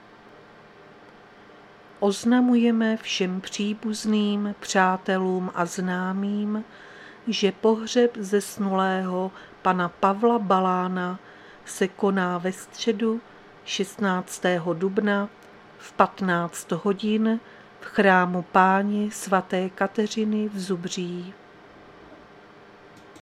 Záznam hlášení místního rozhlasu 15.4.2025